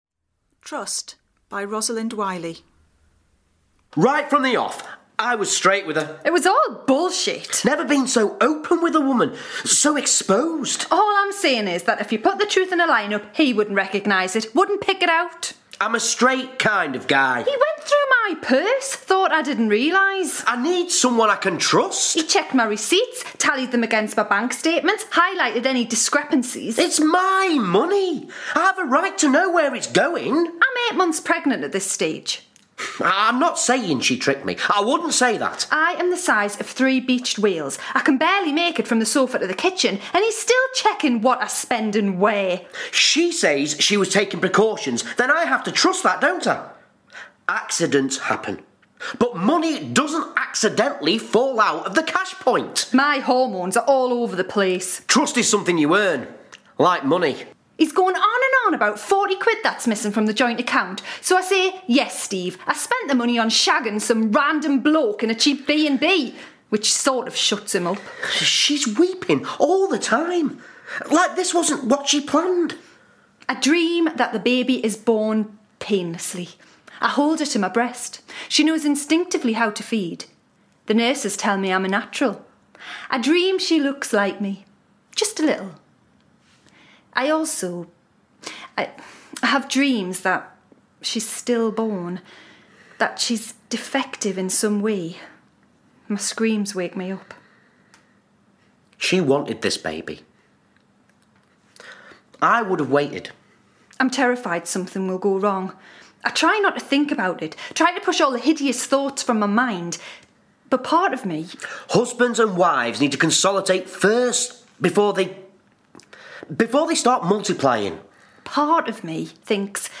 A monologue